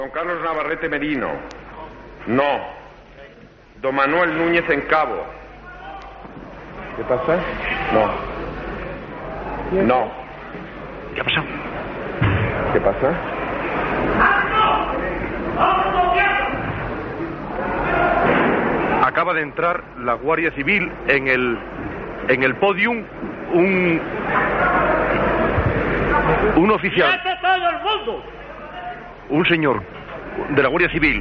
Informatiu
Com el resultat de la votació es preveia clarament favorable al candidat, en aquell moment, la transmissió de la votació de Radio Nacional de España s'estava fent per Radio 3.